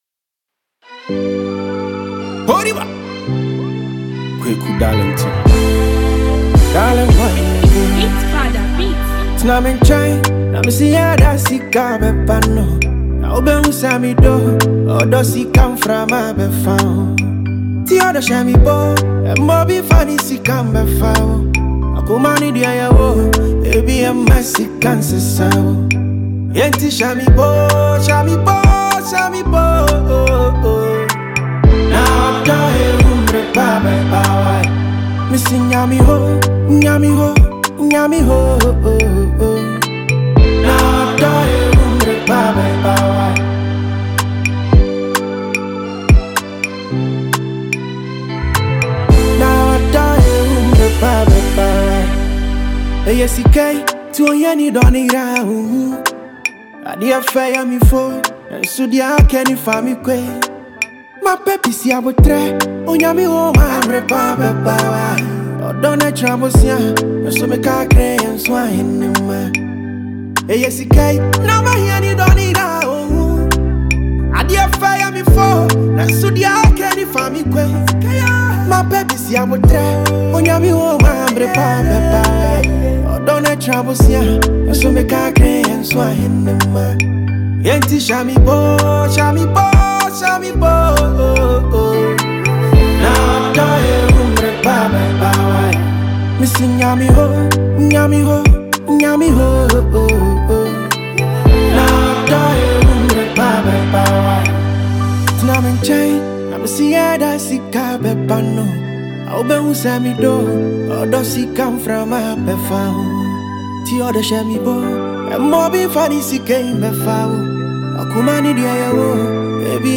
lyrical warmth and melodic delivery